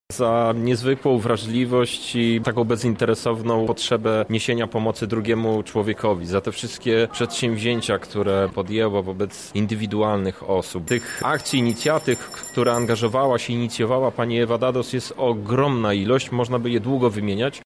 – mówi Krzysztof Hetman.